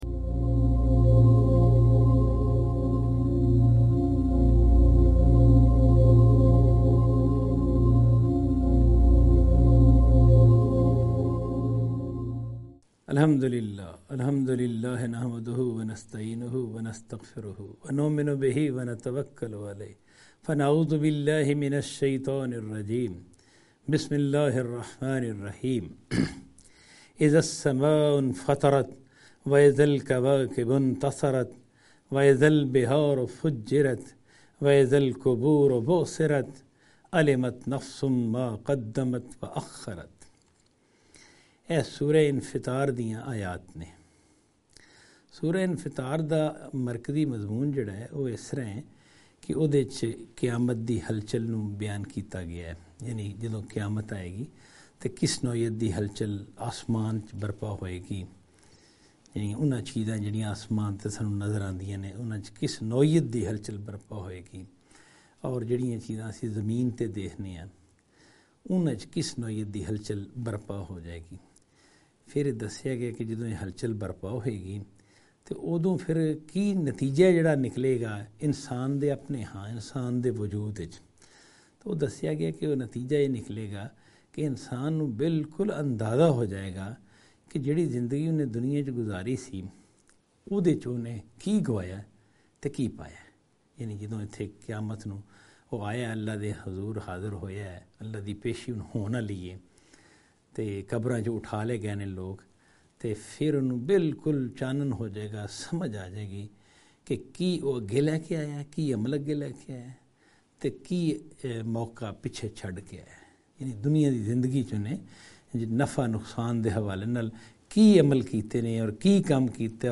Qur'anic Surah Lecture series in Punjabi